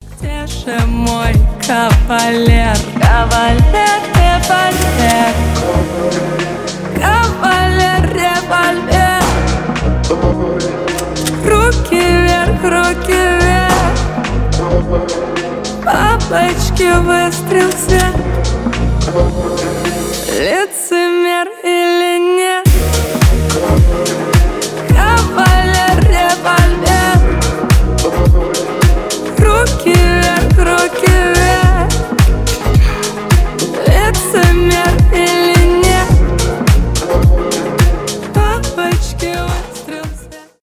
Поп Женский голос